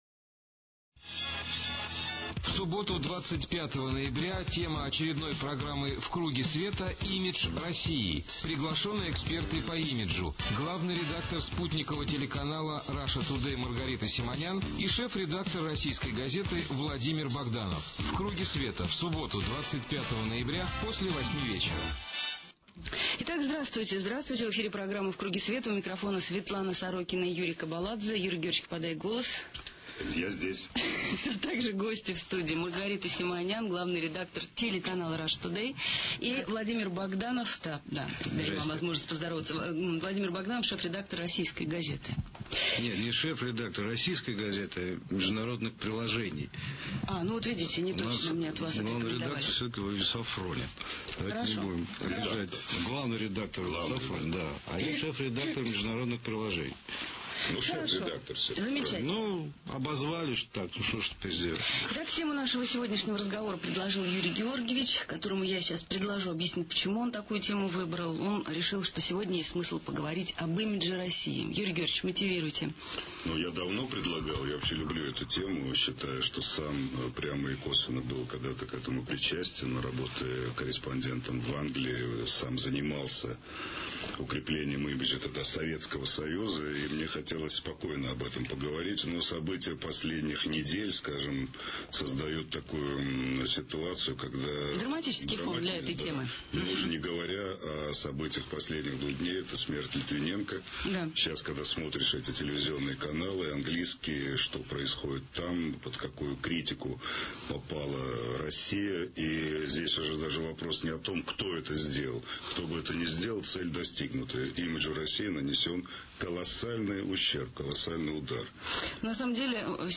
Светлана Сорокина: передачи, интервью, публикации